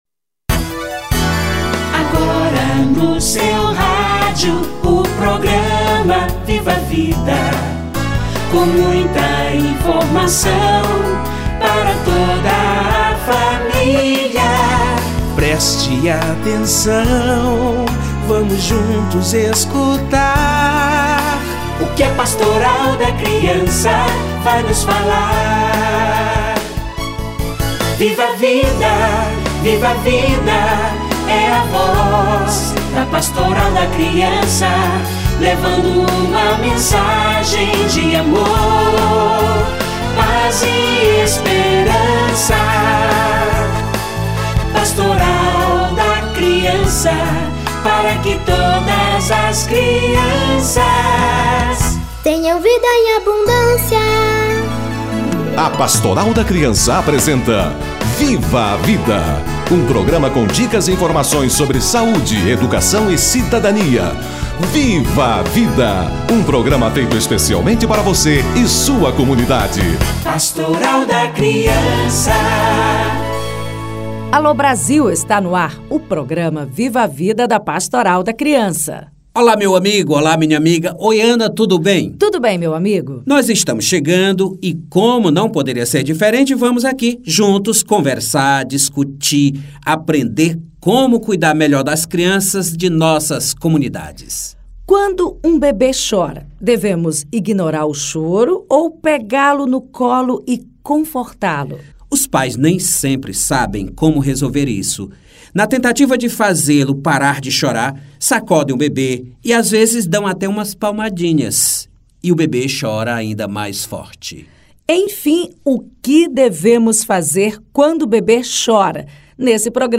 Comunicação do bebê - Entrevista